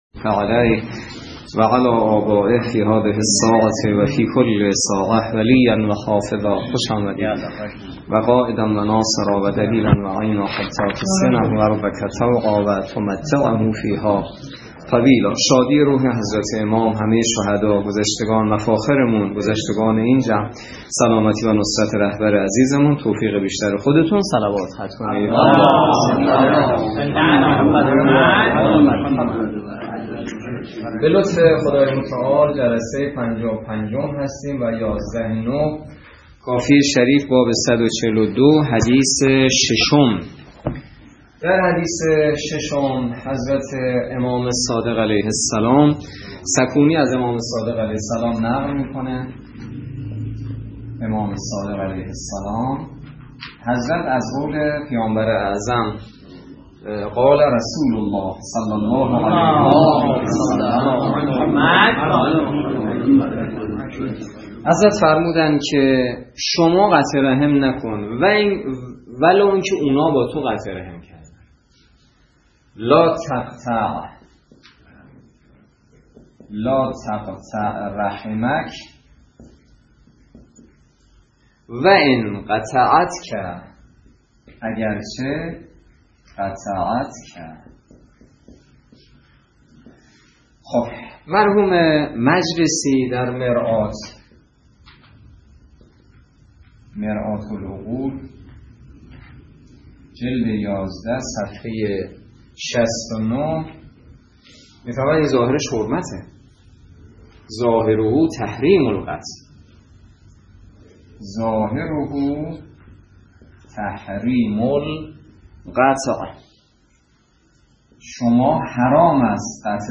درس فقه الاجاره نماینده مقام معظم رهبری در منطقه و امام جمعه کاشان - سال سوم جلسه پنجاه و پنج